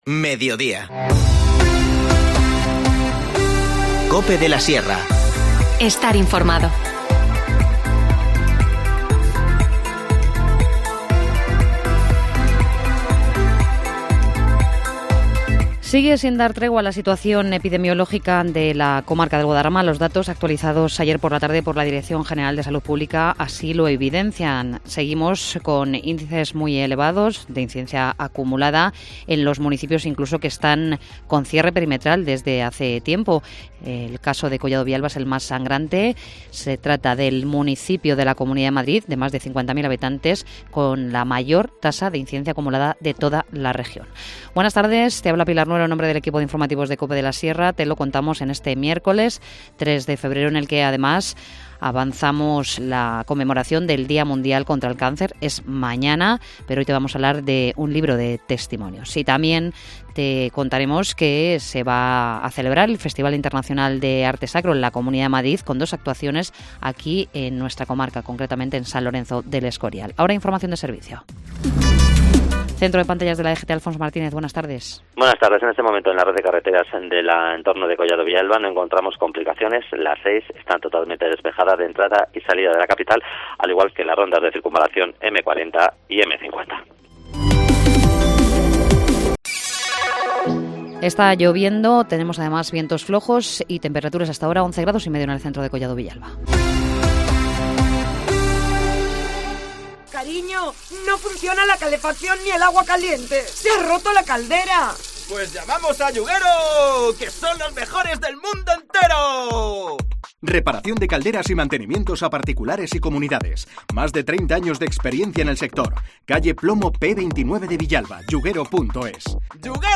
Informativo Mediodía 3 febrero